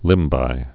(lĭmbī)